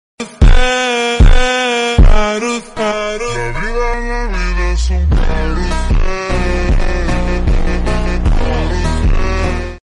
SHER ROAR 🔥 sound effects free download